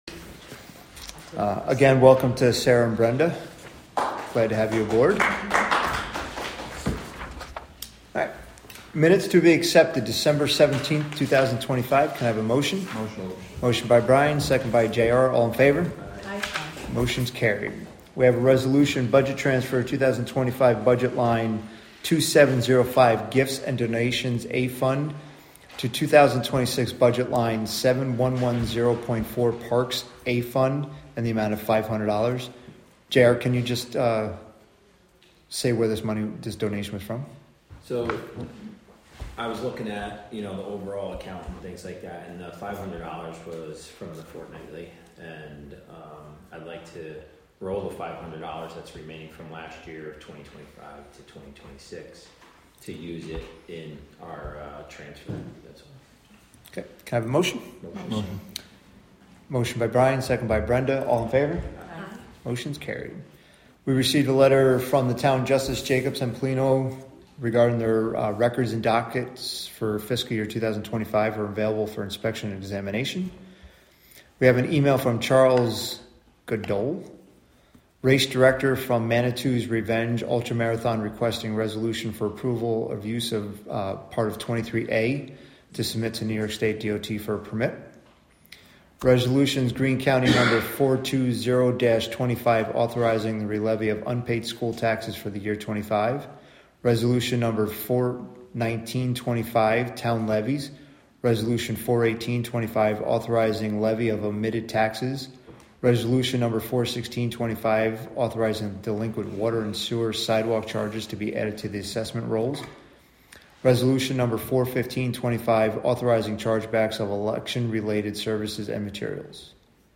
Live from the Town of Catskill: January 6, 2026 Catskill Town Board Meeting (Audio)